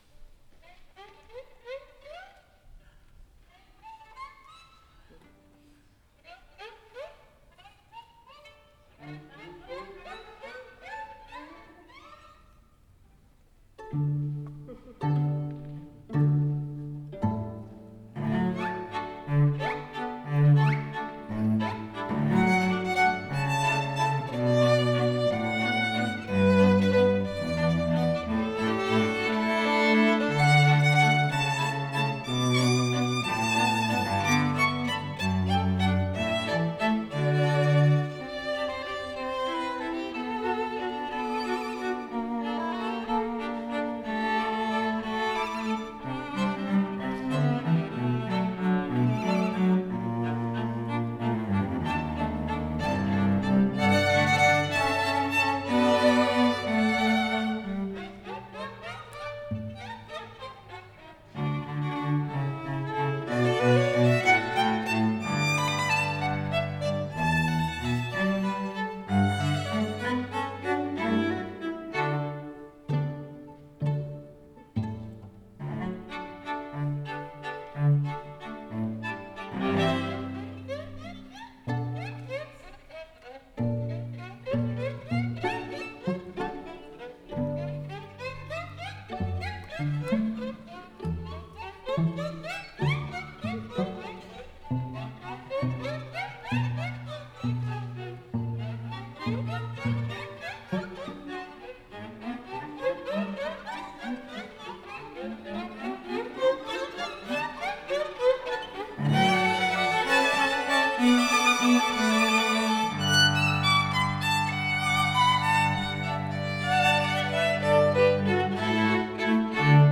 for String Quartet (2022)